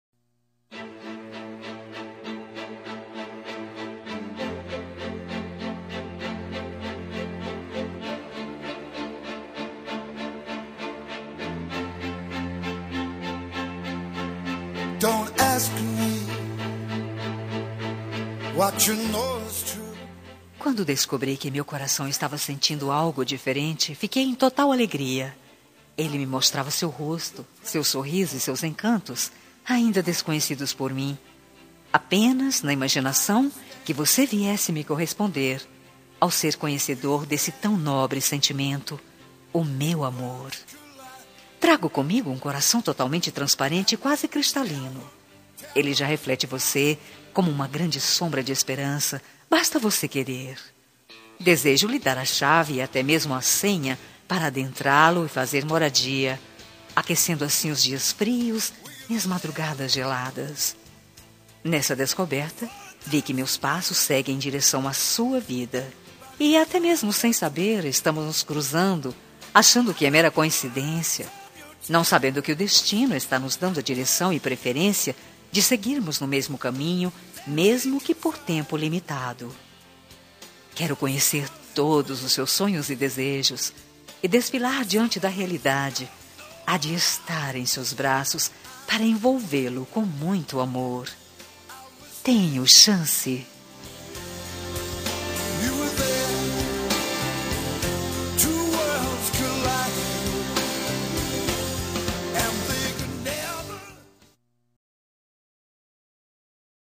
Telemensagem de Conquista – Voz Feminina – Cód: 140110